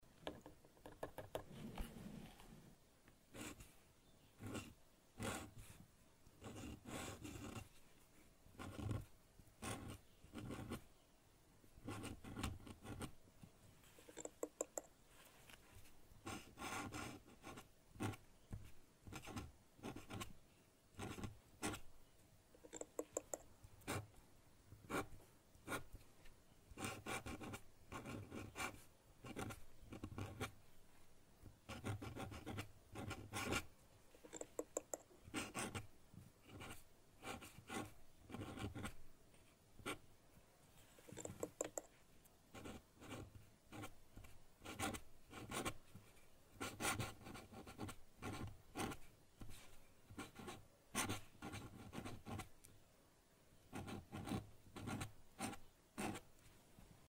Звуки пера по бумаге
Пером пишут и макают в чернильницу